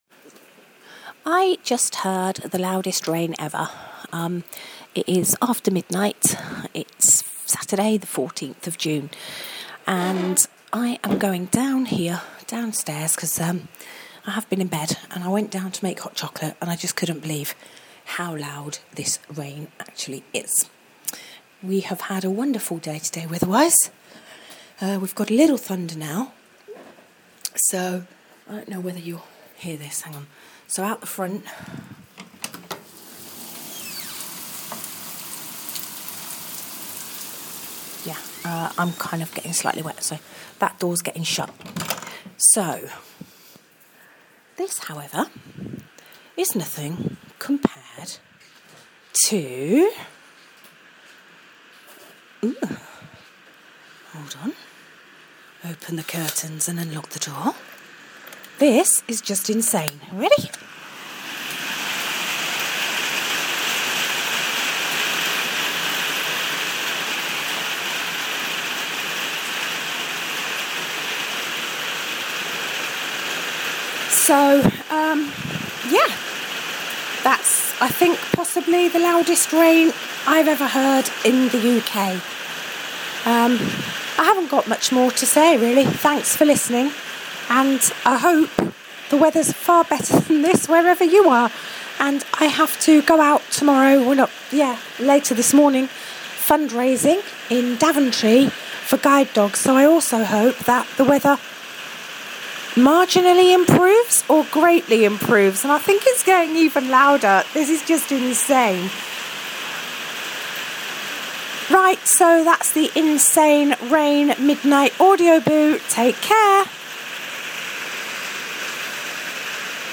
Insane rain audio boo (sometime around midnight)